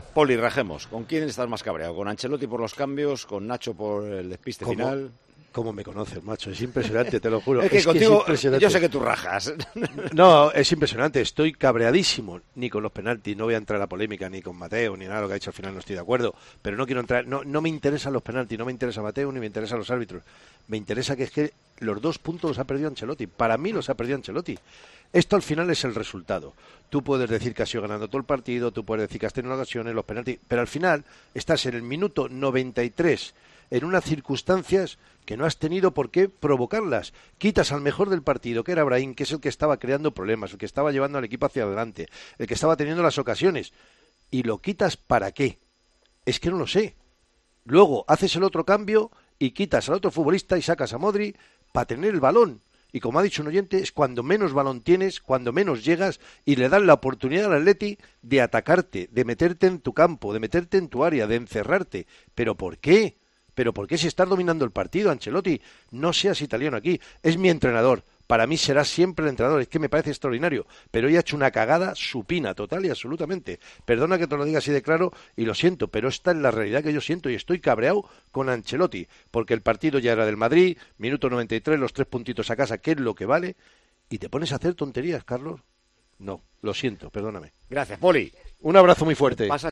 El comentarista del Real Madrid en Tiempo de Juego mostró su enfado tras el empate de los blancos en el minuto 93 con un gol de Llorente.